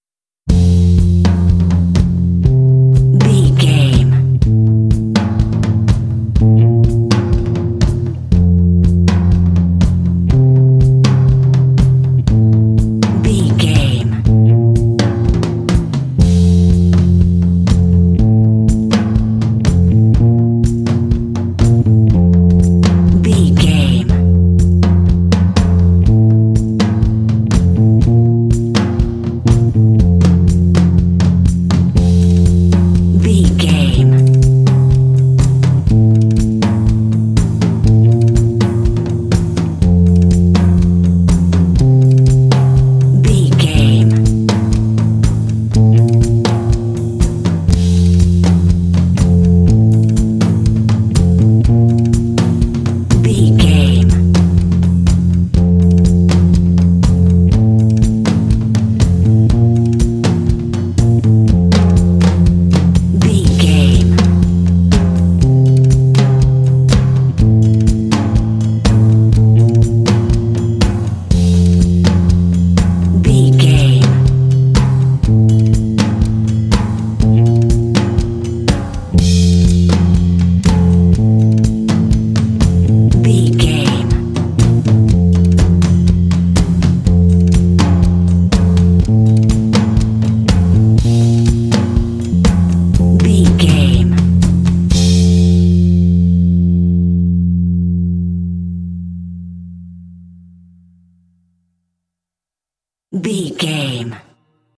Uplifting
Ionian/Major
smooth
calm
drums
bass guitar
indie
alternative rock
contemporary underscore